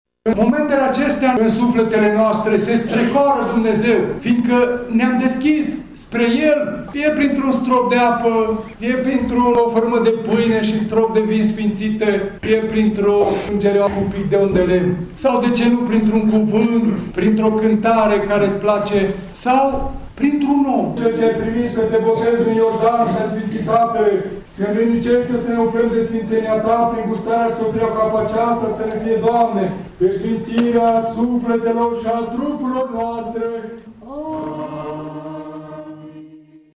Boboteaza, o mare sărbătoare creştină şi populară, a fost celebrată și la bisericuța din localitatea mureșeană Vidrasău.